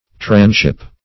transship - definition of transship - synonyms, pronunciation, spelling from Free Dictionary
Transship \Trans*ship"\
transship.mp3